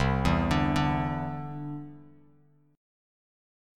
Cadd9 Chord